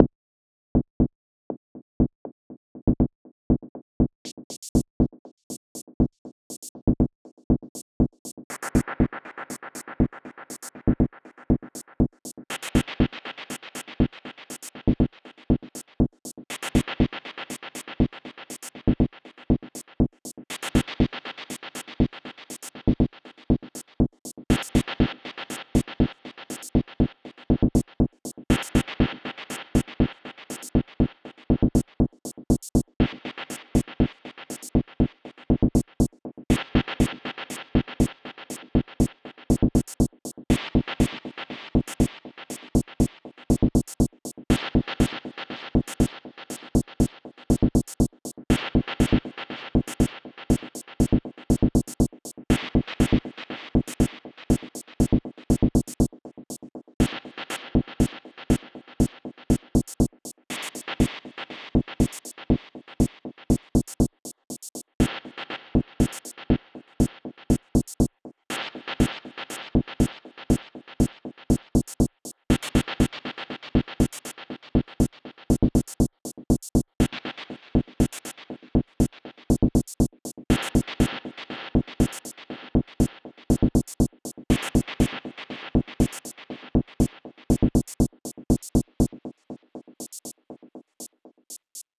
Pieza Intelligent dance music (IDM)
Música electrónica
Dance
melodía
rítmico
sintetizador